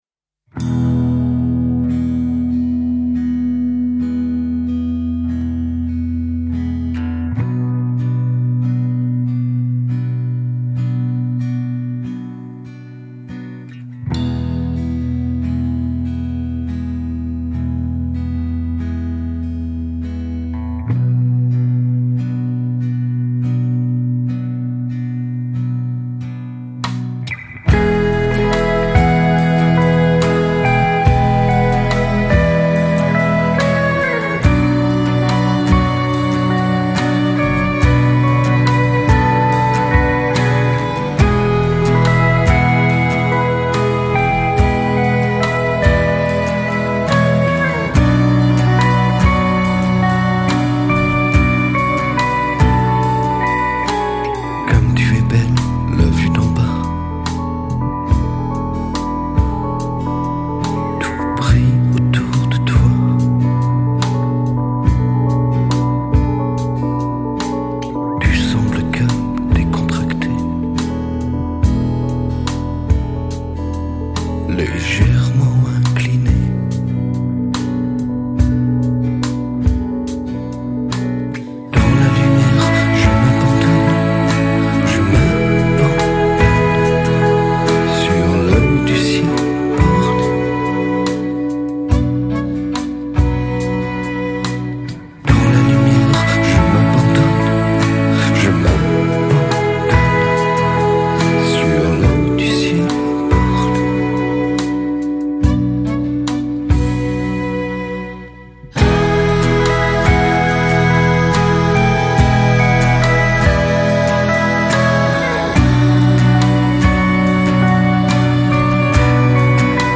Seven virtuoso troubadours
sometimes sung, sometimes whispered